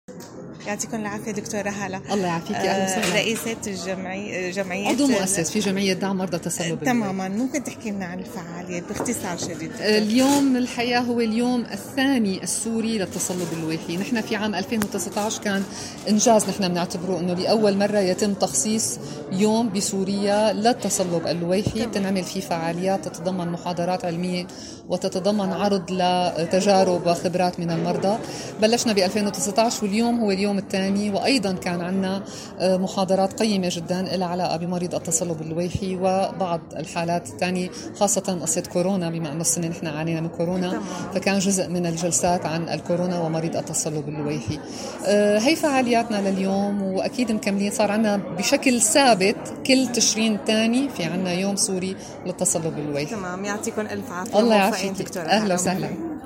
أقيم اليوم بفندق شيراتون دمشق اللقاء الثاني السوري لجمعية مرضى التصلب اللويحي ..